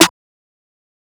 kits/OZ/Snares/Sn (Mafia).wav at ts
Sn (Mafia).wav